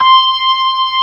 55O-ORG21-C6.wav